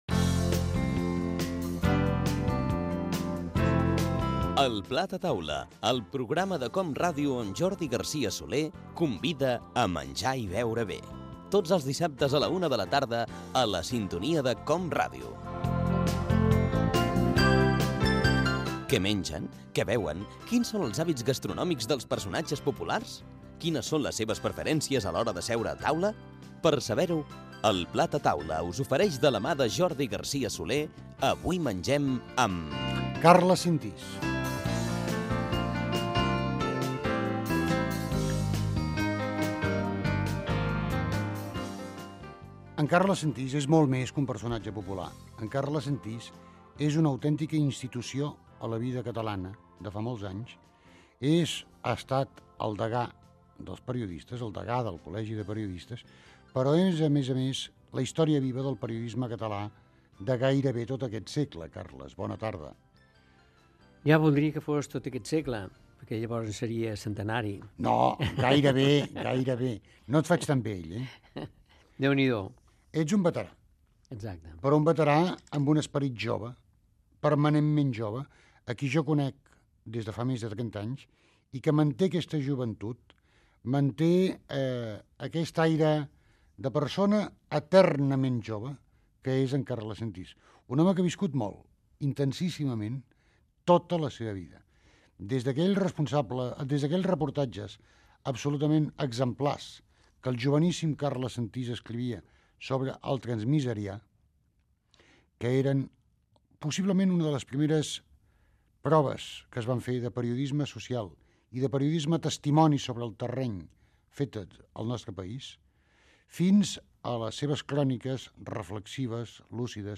8669d65d3ead2332f25628b062c64ca2c3af214b.mp3 Títol COM Ràdio - El plat a taula Emissora COM Ràdio Barcelona Cadena COM Ràdio Titularitat Pública nacional Nom programa El plat a taula Descripció Careta del programa, presentació i entrevista al periodista Carles Sentís.